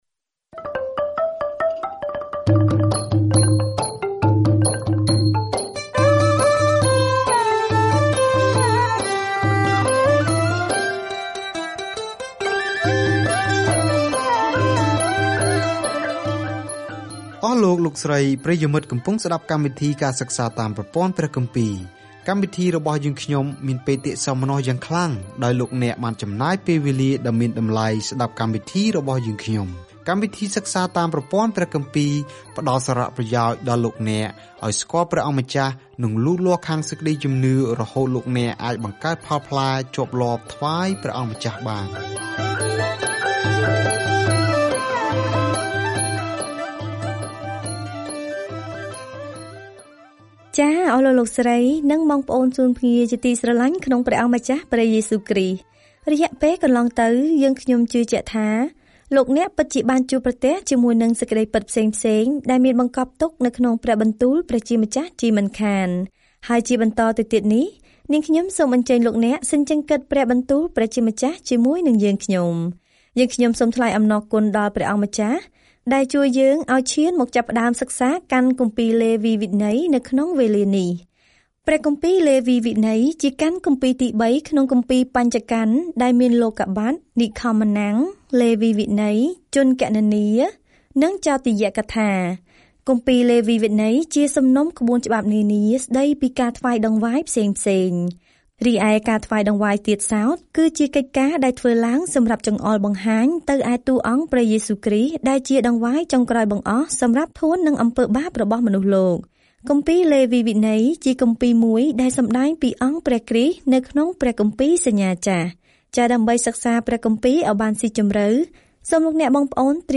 ក្នុងការគោរពប្រណិប័តន៍ យញ្ញបូជា និងការគោរព លេវីវិន័យឆ្លើយសំណួរនោះសម្រាប់ជនជាតិអ៊ីស្រាអែលពីបុរាណ។ ការធ្វើដំណើរជារៀងរាល់ថ្ងៃតាមរយៈលេវីវិន័យ នៅពេលអ្នកស្តាប់ការសិក្សាជាសំឡេង ហើយអានខគម្ពីរដែលជ្រើសរើសពីព្រះបន្ទូលរបស់ព្រះ។